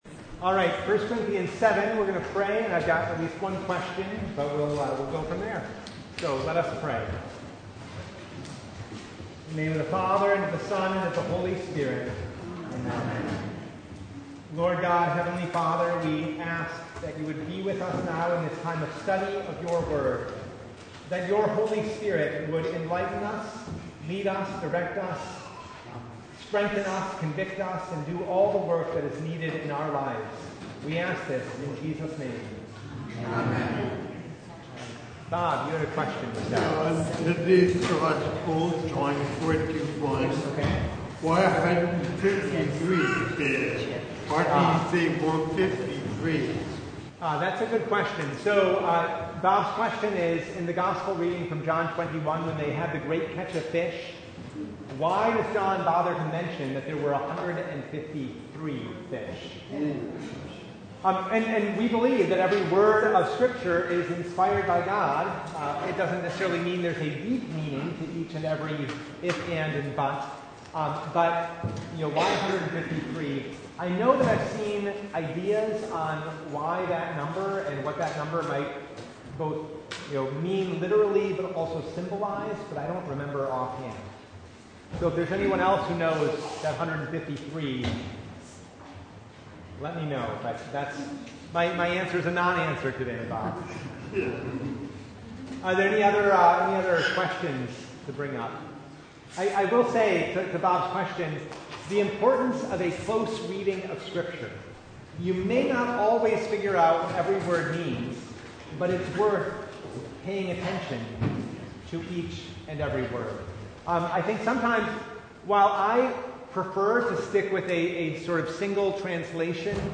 1 Corinthians 7:1-16 Service Type: Bible Hour Topics: Bible Study